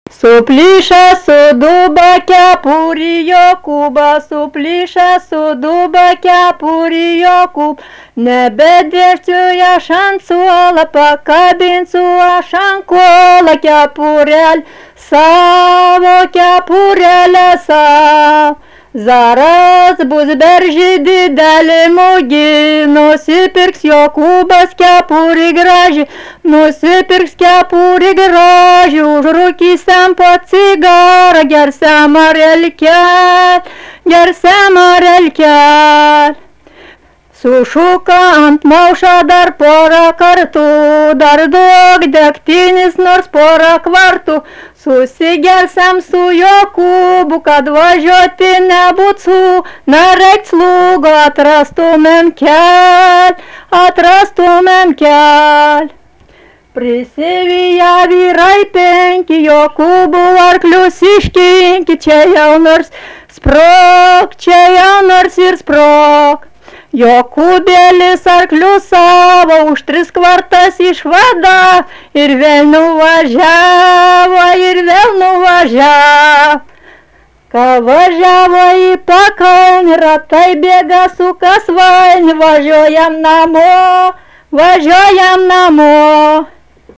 daina
Paškuvėnai
vokalinis